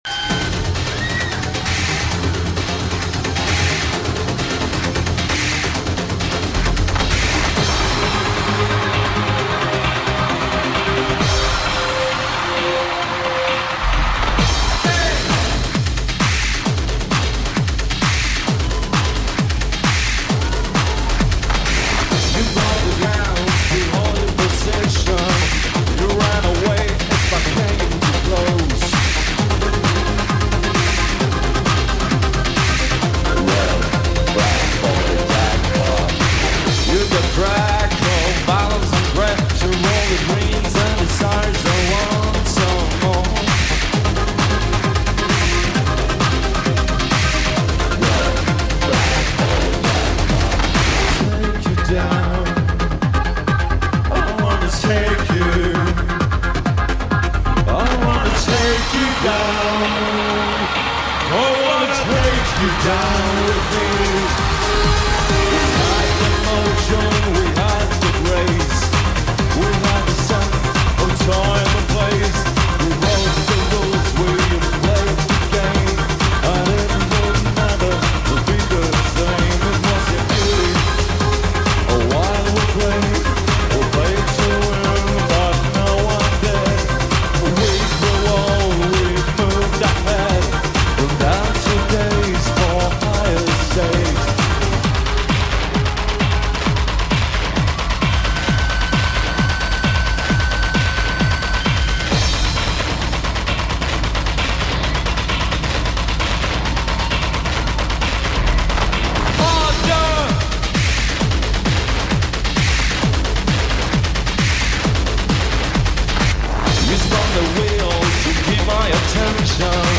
A dose of Swedish electronica
recorded live